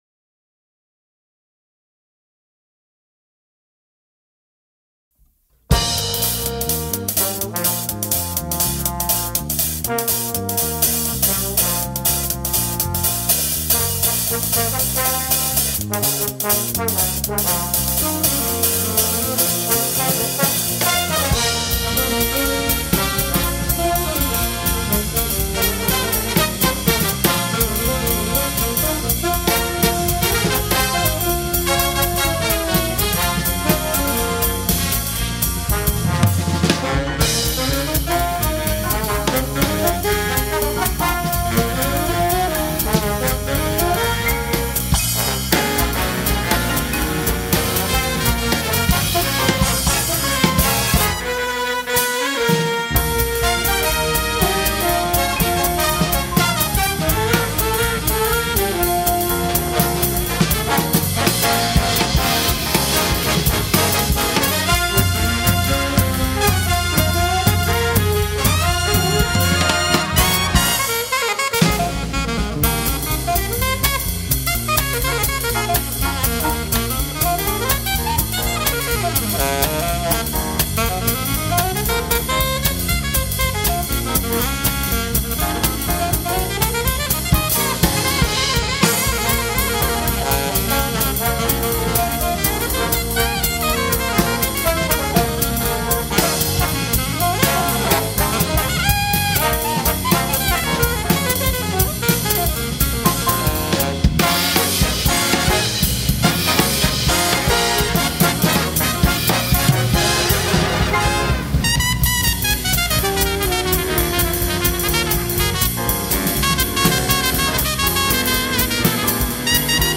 Batterista, didatta, autore.